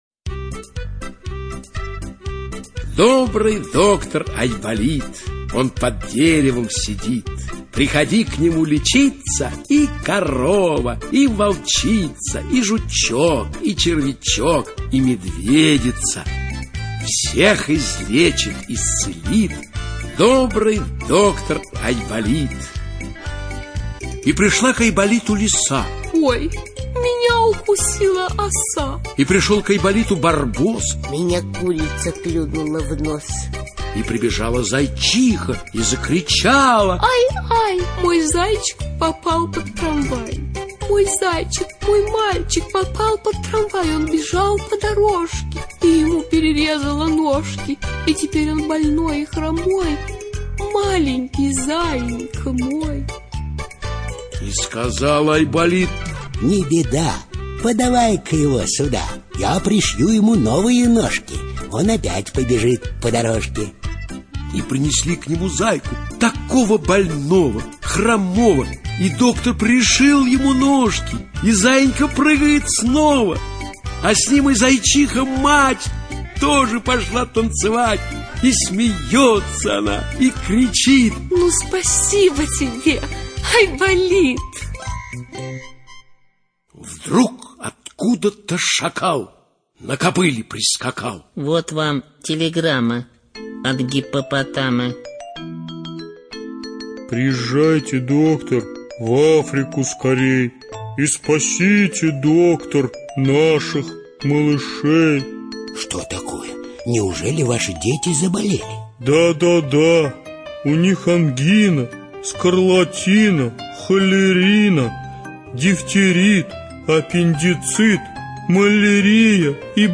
ЧитаютГафт В., Муравьева И., Леньков А., Румянова К., Виторган Э., Баталов А., Абдулов В.